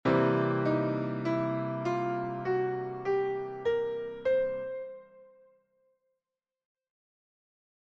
Blues Piano
La blue note est une note étrangère à la gamme initiale, et correspondant au 4ème degré augmenté ( #4 )
blue-note.mp3